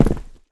jumpland2.wav